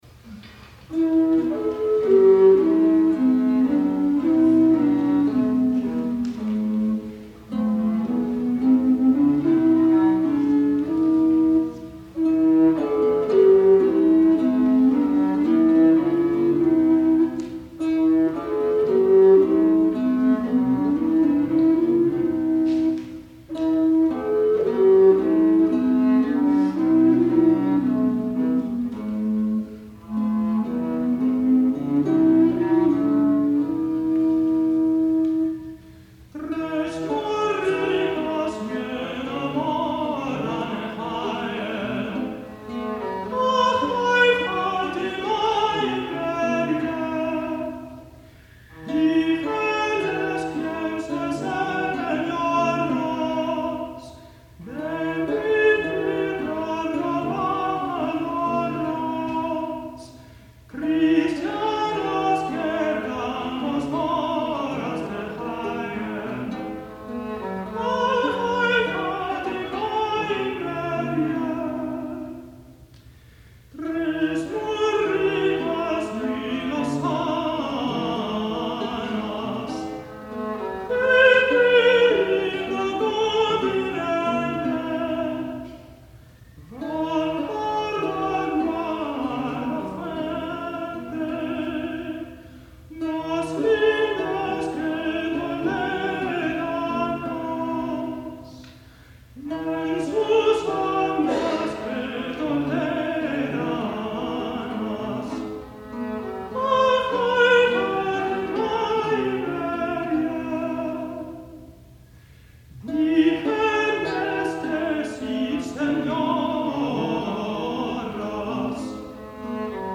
Chamber Consort 'Crescent' 1991
countertenor